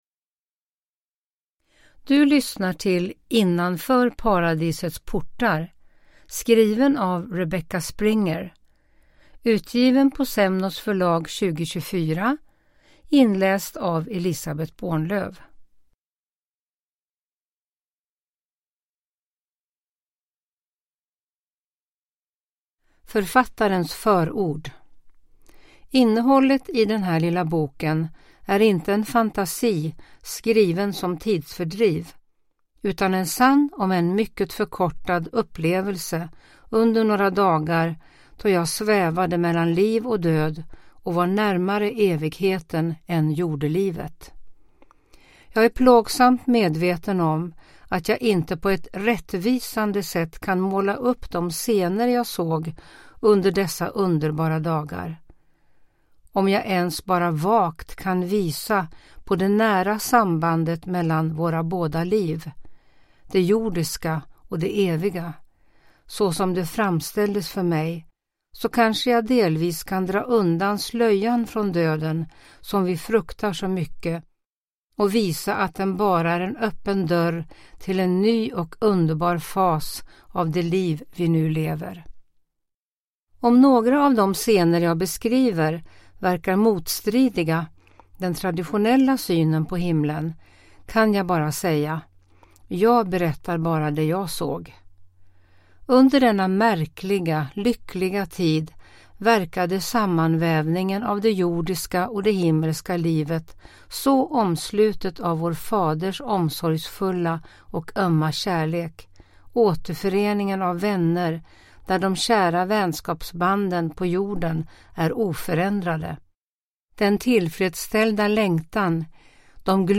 Innanför paradisets portar – Ljudbok